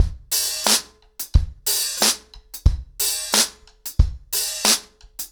ROOTS-90BPM.37.wav